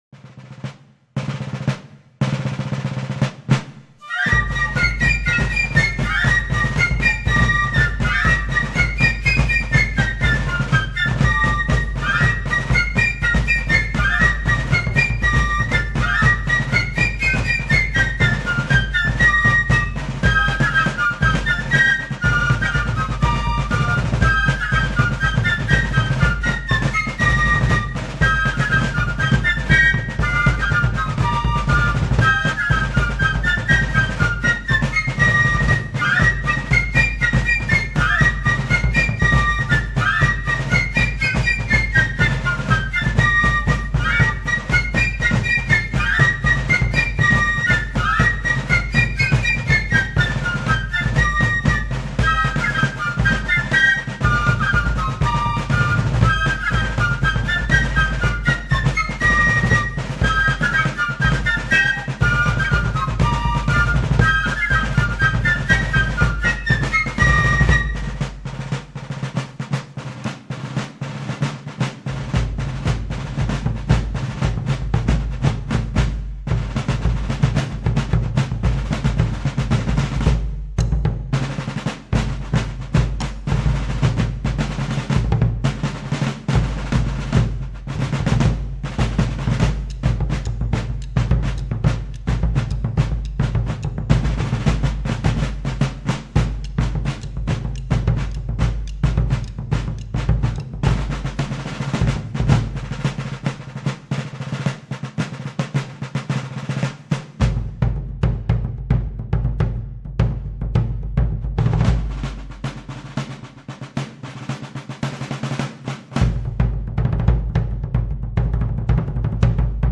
Yankee Doodle (Fife and Drum).mp3